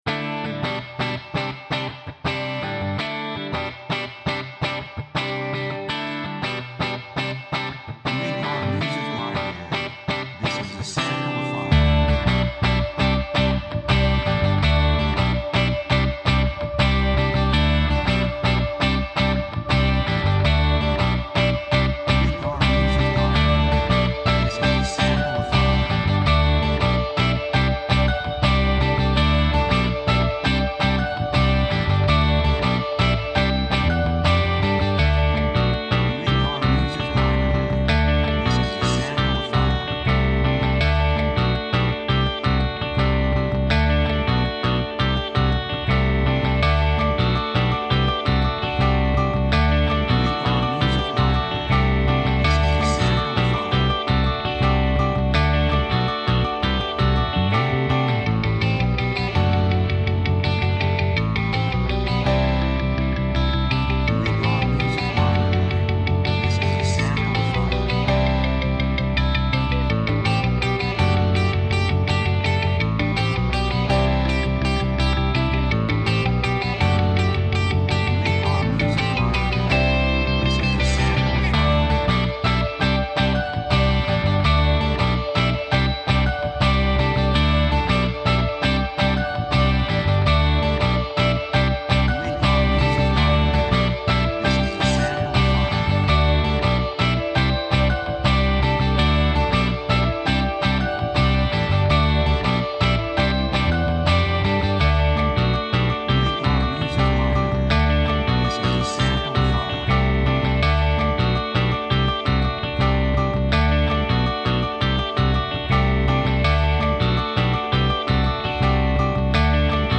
3:25 165 プロモ, ロック